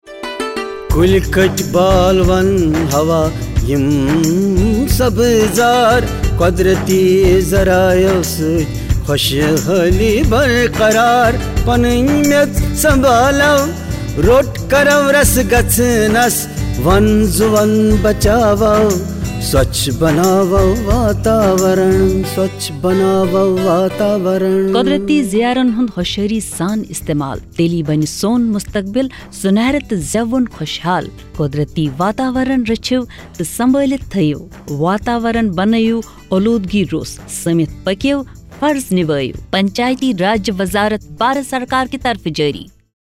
86 Fundamental Duty 7th Fundamental Duty Preserve natural environment Radio Jingle Kashmiri